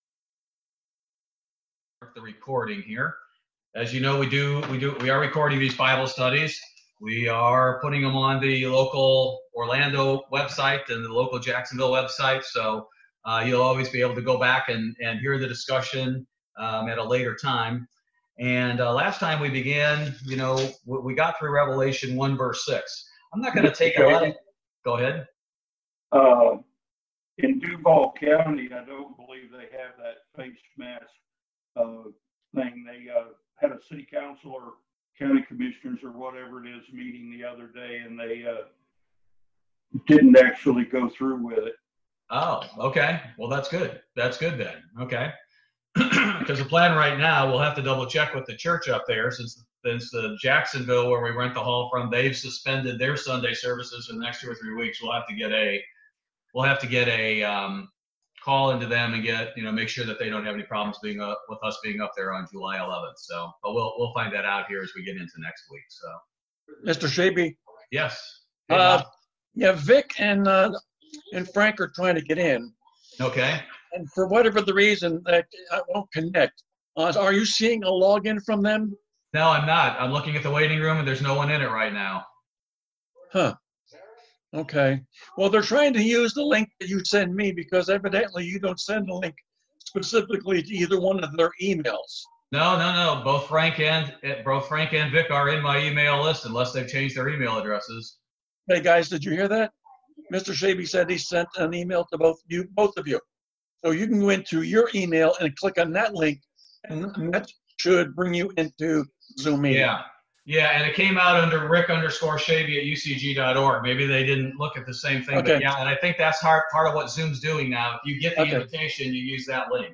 Bible Study - July 1, 2020